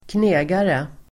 Ladda ner uttalet
Folkets service: knegare knegare substantiv (nedsättande), nine-to-fiver [pejorative] Uttal: [²kn'e:gare] Böjningar: knegaren, knegare, knegarna Synonymer: arbetare Definition: (välanpassad) person med ordnat arbete